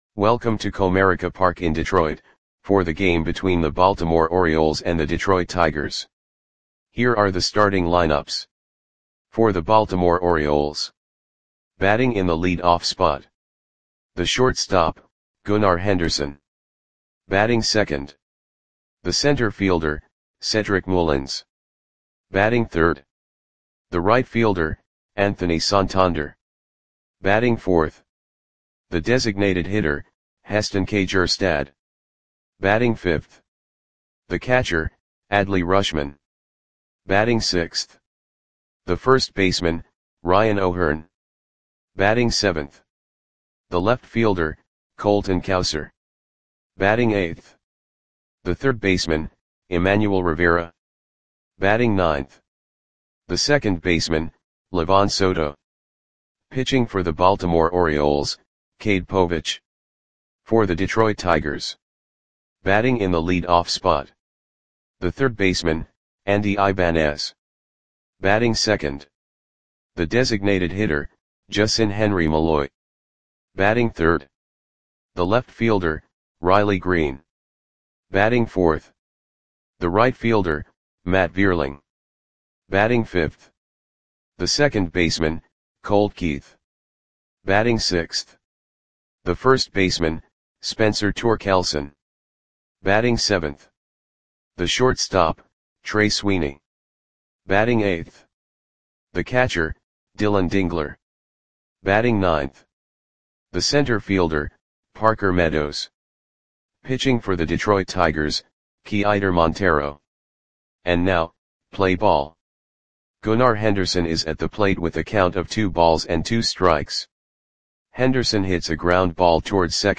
Audio Play-by-Play for Detroit Tigers on September 15, 2024
Click the button below to listen to the audio play-by-play.